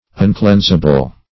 Uncleansable \Un*cleans"a*ble\, a. Incapable of being cleansed or cleaned.